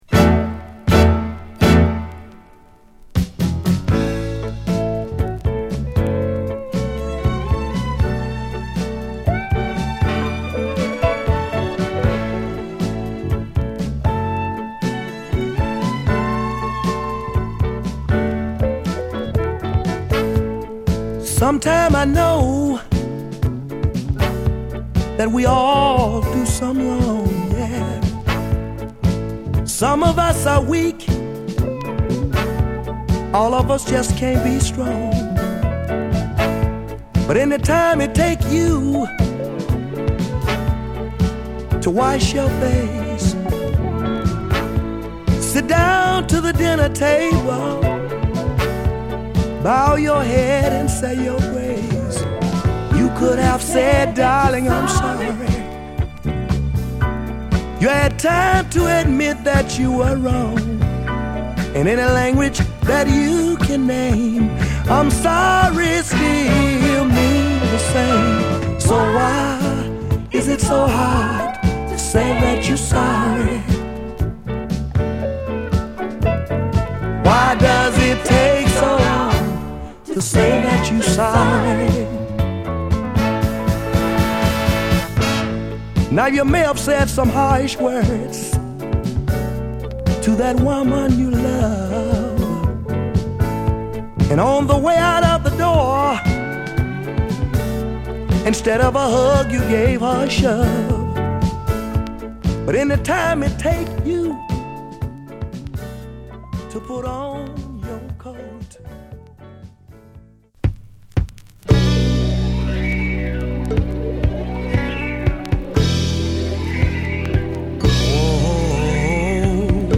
グッドダンストラックに乗ったナイスヴォーカルを聴かせる1枚です。